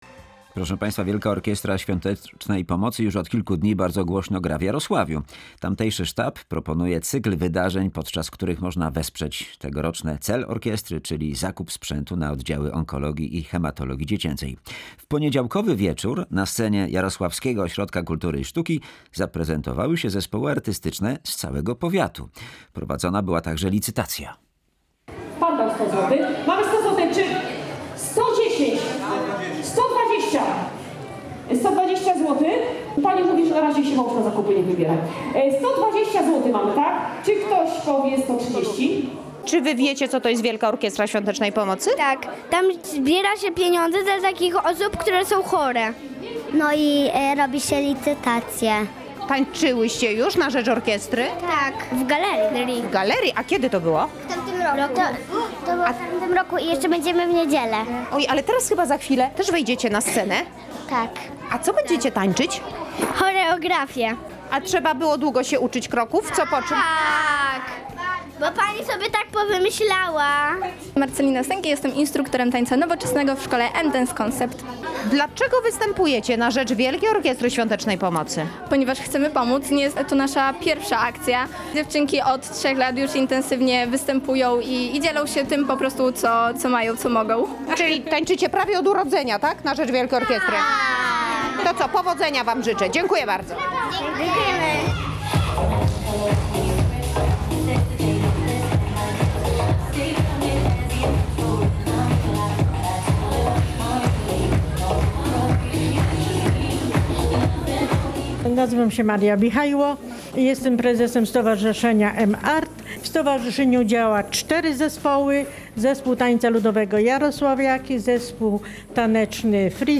W poniedziałkowy wieczór, na scenie Jarosławskiego Ośrodka Kultury i Sztuki zaprezentowały się zespoły artystyczne z całego powiatu, prowadzone były też licytacje.